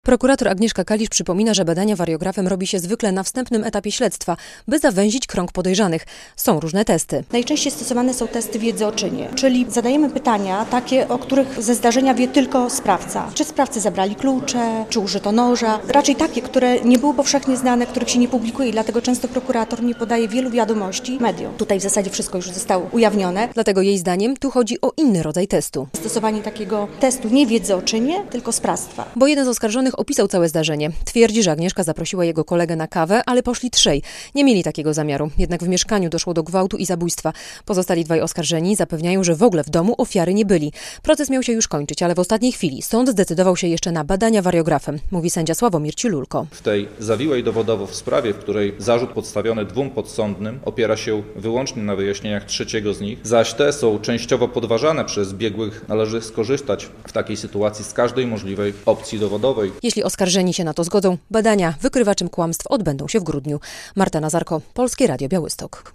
Wariograf w sprawie zabójstwa sokólczanki - relacja